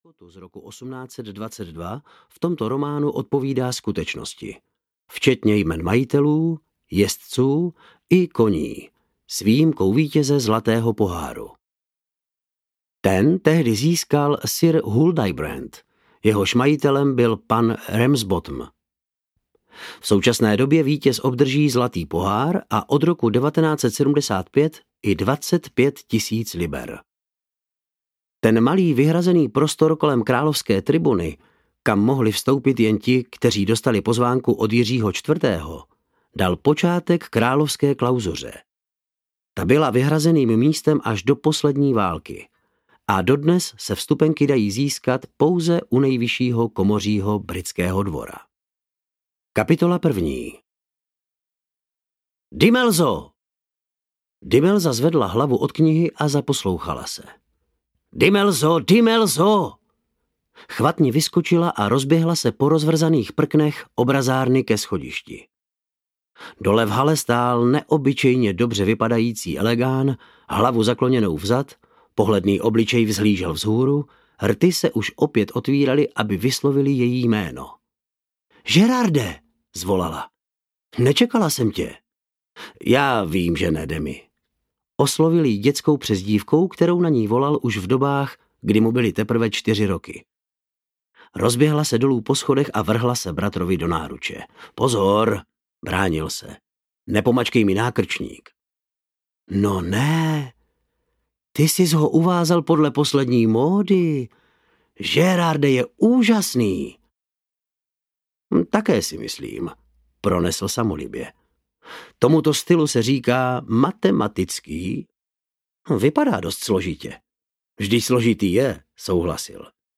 Zamilovaný duch audiokniha
Ukázka z knihy